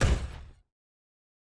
pgs/Assets/Audio/Sci-Fi Sounds/MISC/Footstep Robot Large 2_02.wav at master
Footstep Robot Large 2_02.wav